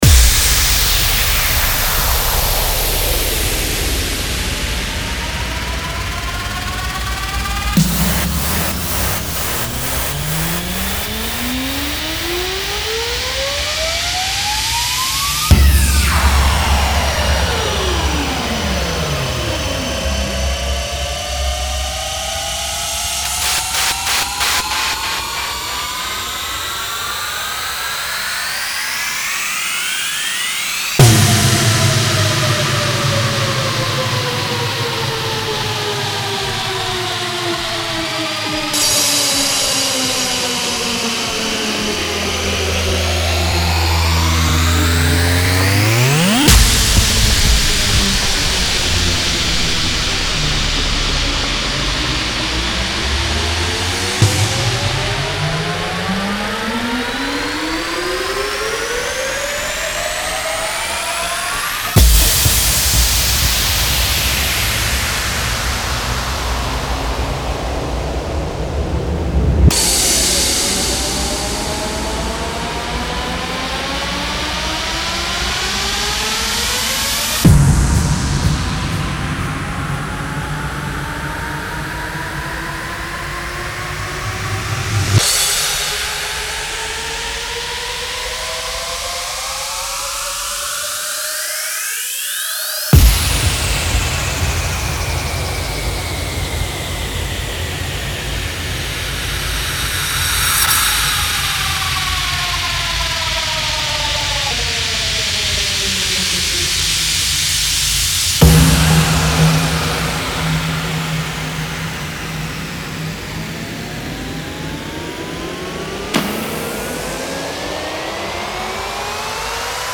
• 300 Drops • 300 Impacts • 300 Rises • Key & Tempo Labelled • 44.1kHz/24-Bit • 100% Royalty-Free
A whopping 2.76GB pack containing the latest crescendo-building risers, speaker-quaking drops, insane snare builds, and section defining impacts, make this title a must have for all your effects and breakdown needs.
All loops are key and tempo labelled at 124, 128 and 140 BPM, so you can get creative and save precious time producing your next killer release.